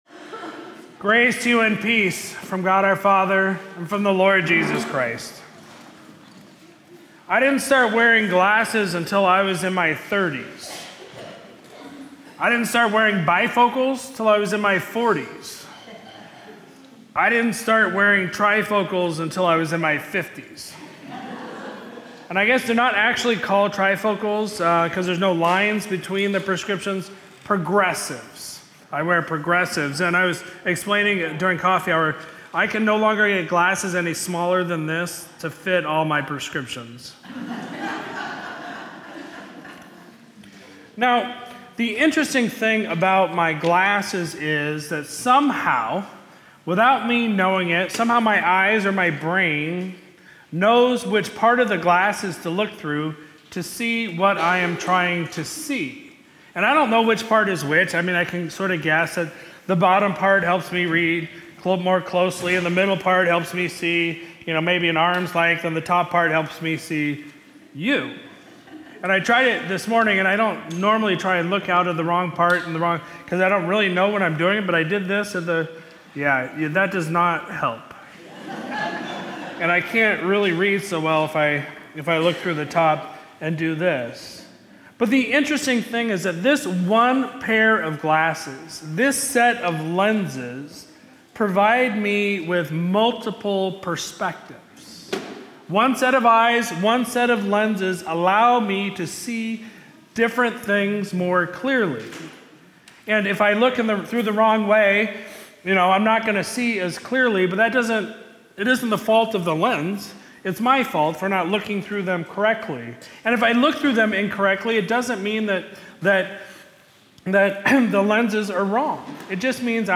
Sermon from Sunday, December 15, 2024